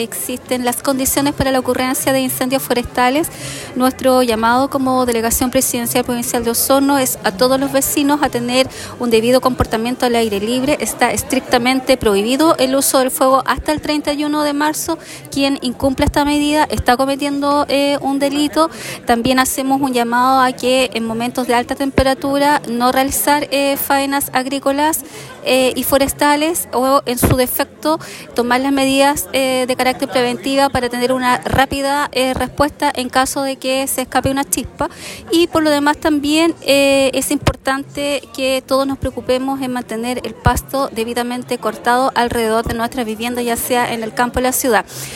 La delegada presidencial, Claudia Pailalef, señaló que las actuales condiciones climáticas aumentan el riesgo de incendios forestales, razón por la cual recordó que las quemas agrícolas están prohibidas hasta el 31 de marzo. Además, enfatizó que, en jornadas de altas temperaturas, las faenas agrícolas deben ser suspendidas para evitar cualquier incidente.